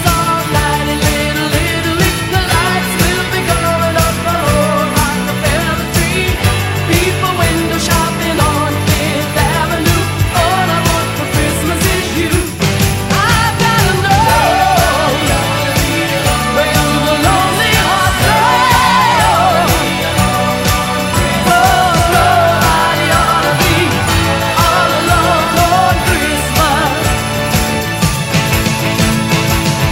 • Christmas: Rock